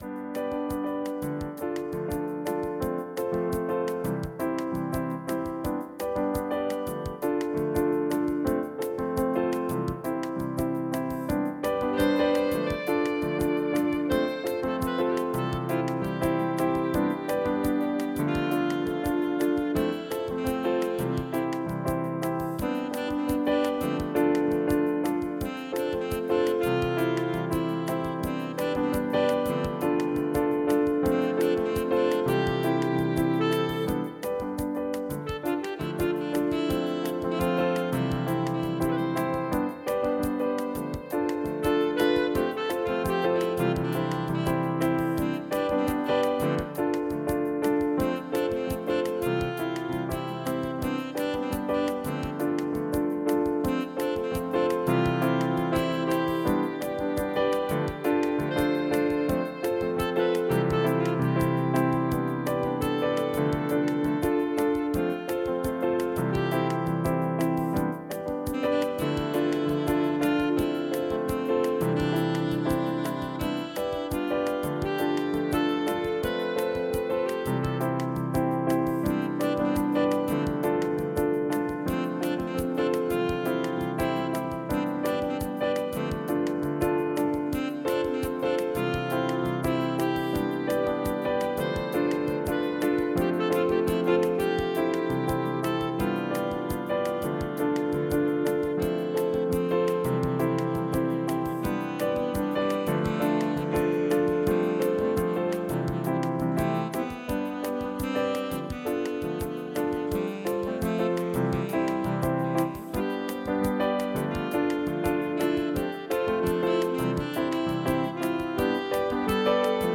Tempo: 83 bpm / Datum: 07.07.2017
Latin/Creative Commons License 4.0 / noncommercial use free